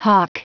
Prononciation du mot hawk en anglais (fichier audio)
Prononciation du mot : hawk